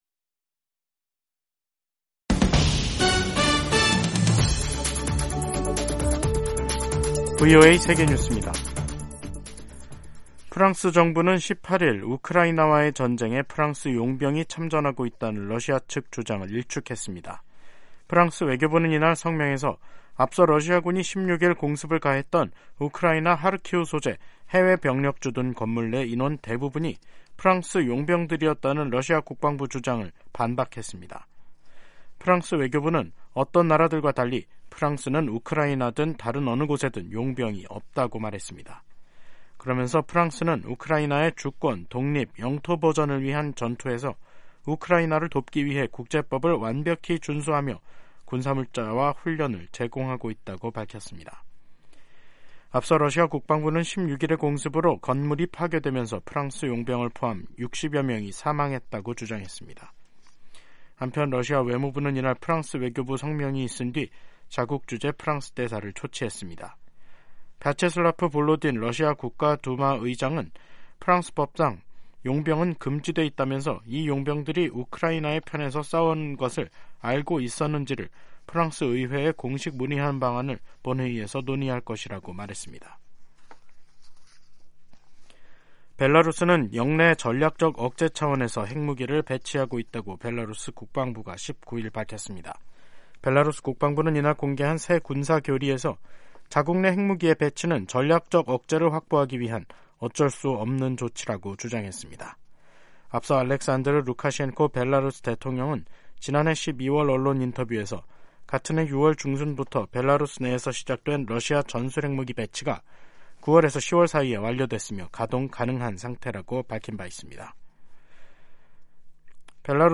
세계 뉴스와 함께 미국의 모든 것을 소개하는 '생방송 여기는 워싱턴입니다', 2024년 1월 19일 저녁 방송입니다. '지구촌 오늘'에서는 베냐민 네타냐후 이스라엘 총리가 팔레스타인 독립국가 수립에 반대 입장을 밝힌 소식 전해드리고, '아메리카 나우'에서는 도널드 트럼프 전 대통령이 자신의 대선 출마를 막으면 안 된다고 결정해 줄 것을 대법원에 촉구한 이야기 살펴보겠습니다.